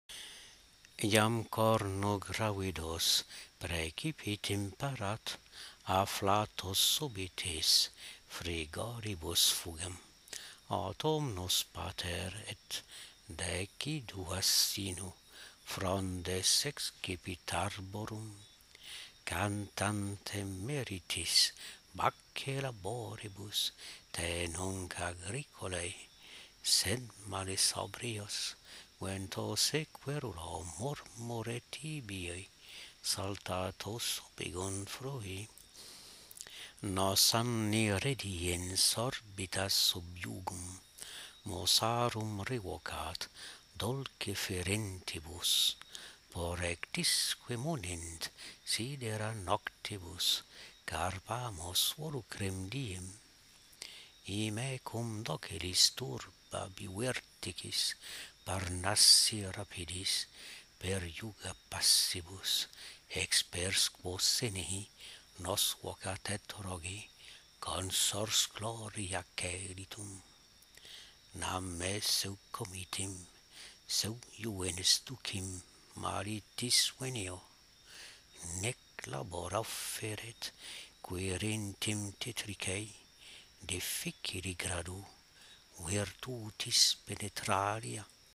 Enregistrement du poème en latin (strophe asclépiade A) :